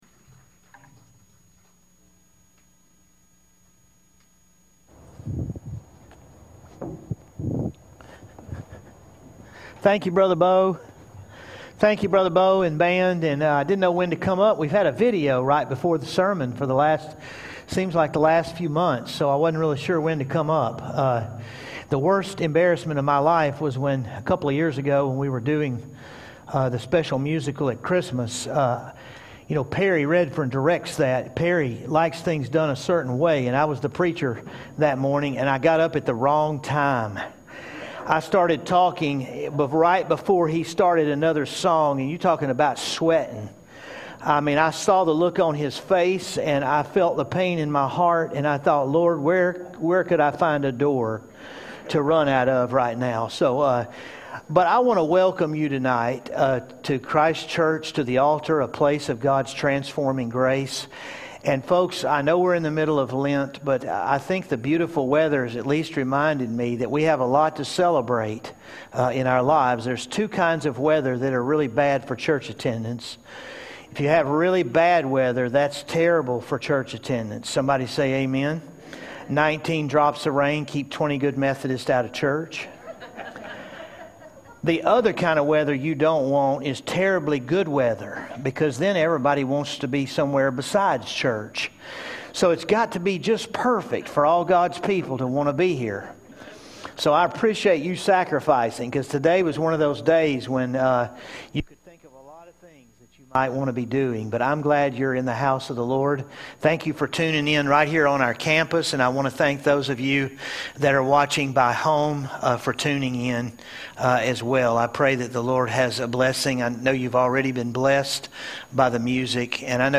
Saturday night, Week 3 of our Lenten series "Liar, Lunatic, or Lord".